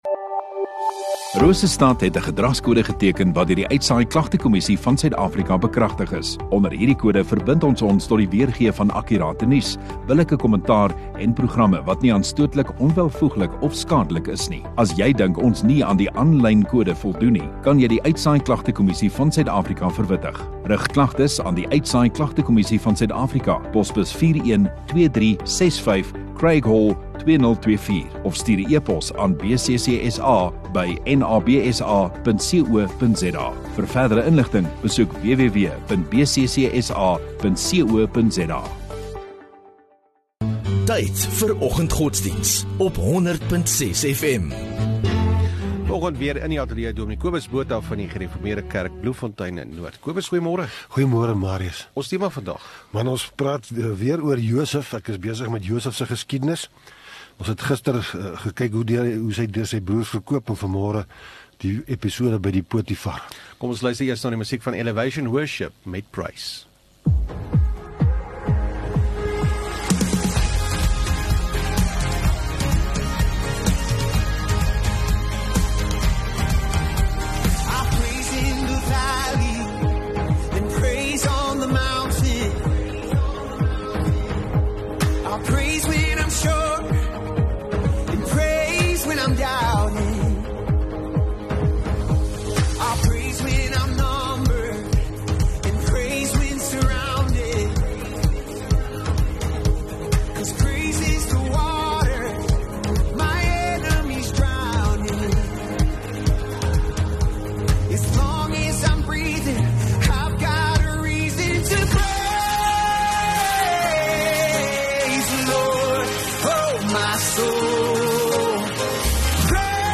10 Sep Dinsdag Oggenddiens